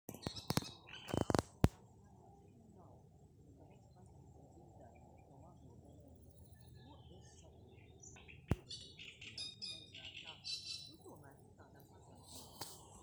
Great Reed Warbler, Acrocephalus arundinaceus
StatusSinging male in breeding season